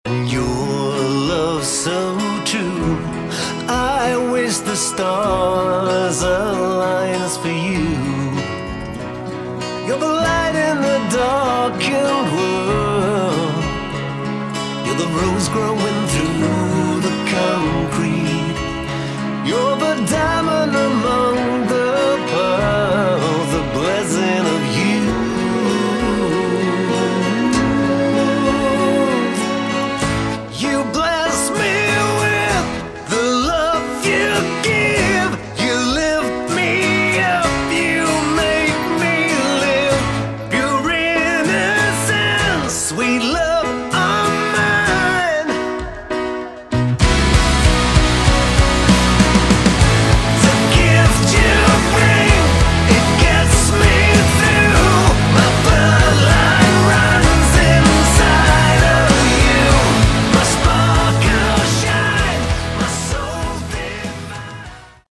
Category: Hard Rock / Melodic Metal
guitars, keyboards
bass
drums